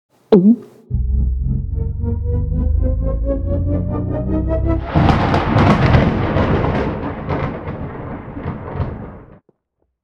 potion.wav